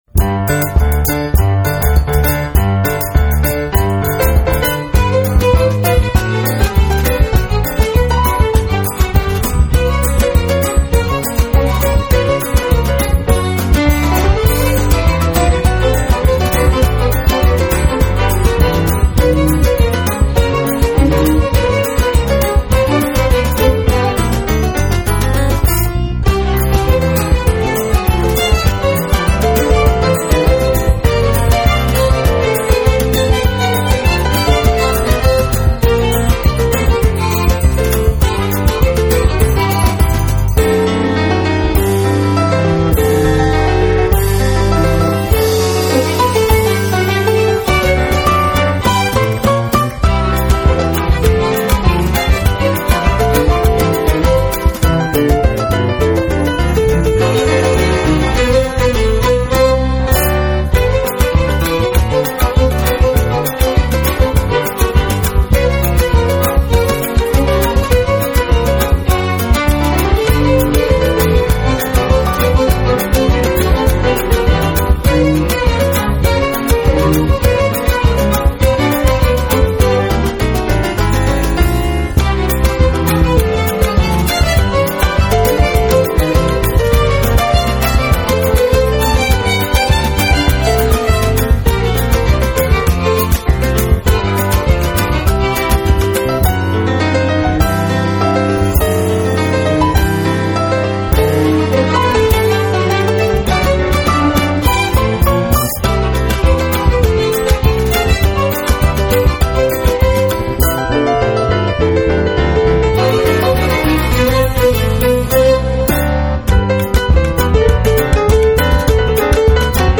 ヴァイオリンを中心とした弦楽器のエレガントな旋律と、ジャズやカリビアンの要素が一体となったオリジナルなサウンドは健在！
WORLD / CD / NEW RELEASE